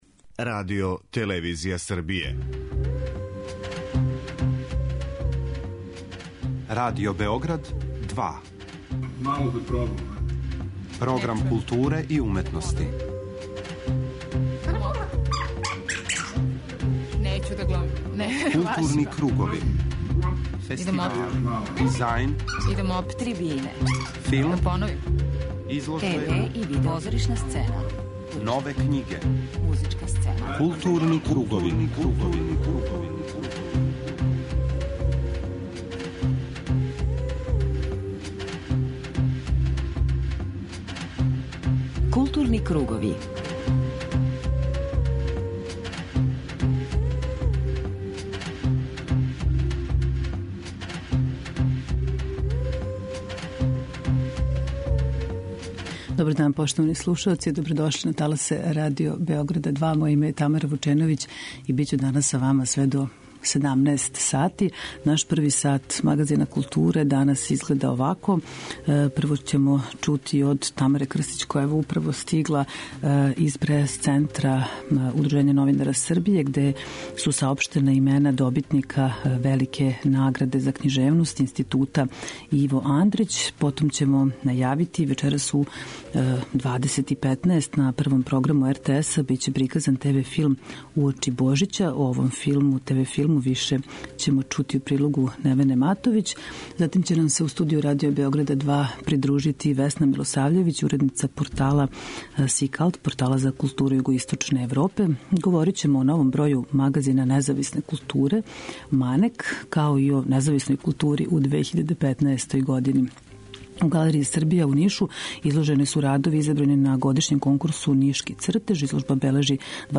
У првом делу емисије упознаћемо вас са актуелним културним догађајима, а у тематском делу 'Златни пресек', посвећеном визуелним уметностима, имаћемо две гошће.